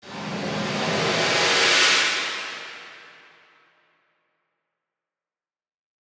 WHITE RISER.wav